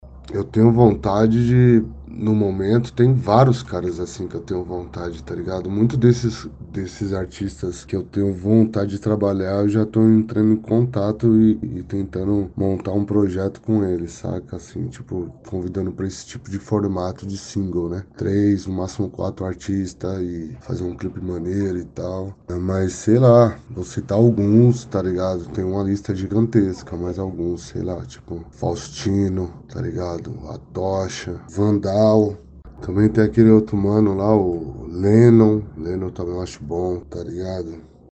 Black Pipe Entrevista